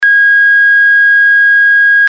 ZUMBADOR CON 8 SONIDOS
Zumbador Electrónico Empotrable para cuadro Ø 22,5MM
dB 86-100